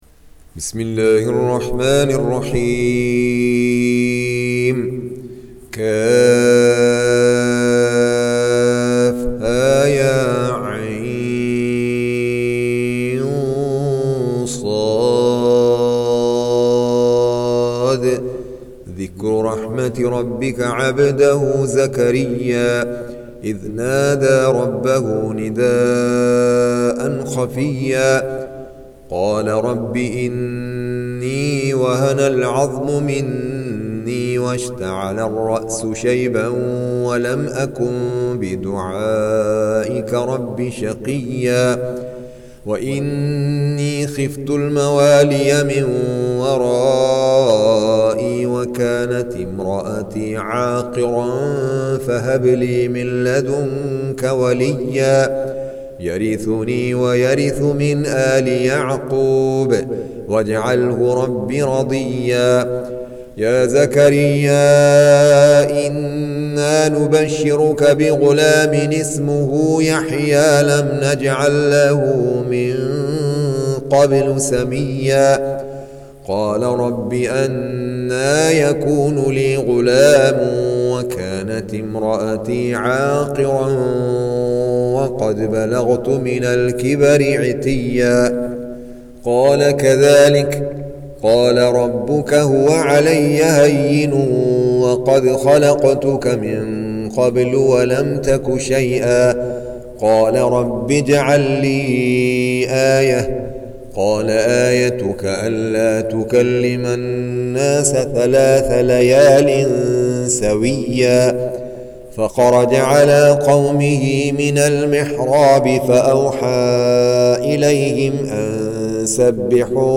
19. Surah Maryam سورة مريم Audio Quran Tarteel Recitation
Surah Repeating تكرار السورة Download Surah حمّل السورة Reciting Murattalah Audio for 19.